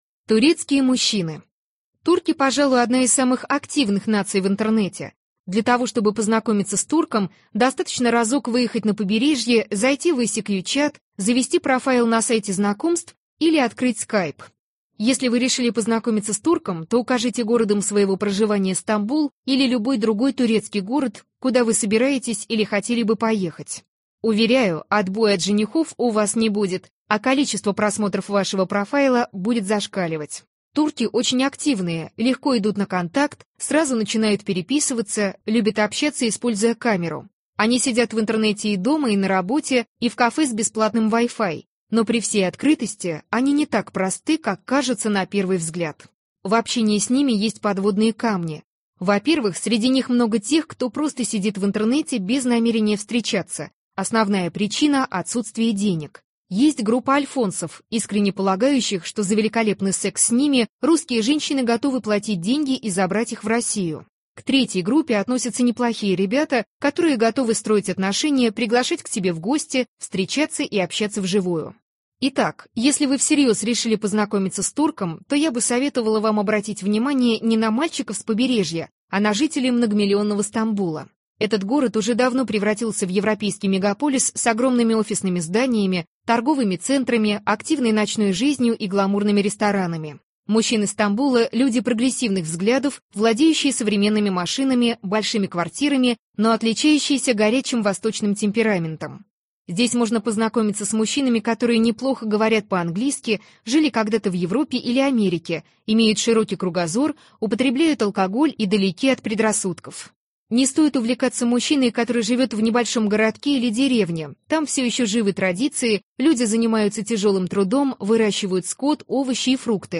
Аудиокнига Как выйти замуж за иностранца | Библиотека аудиокниг
Прослушать и бесплатно скачать фрагмент аудиокниги